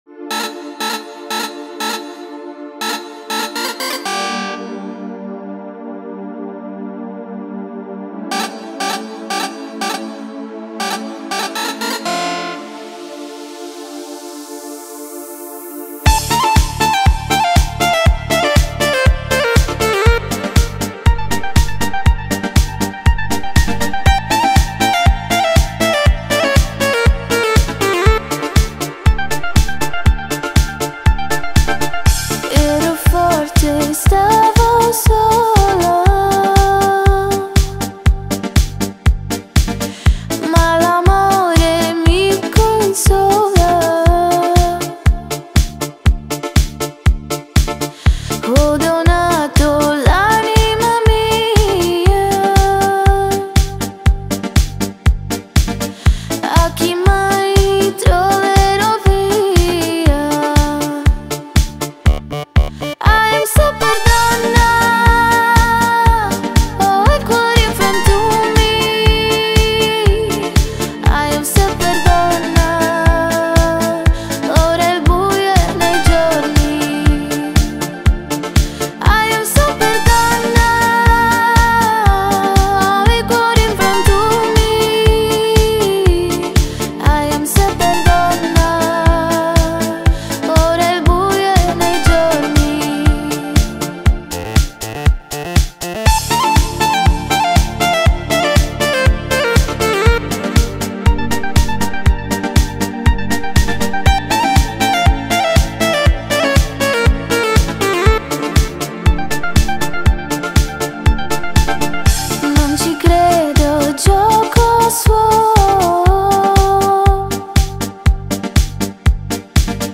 Retro Disco Song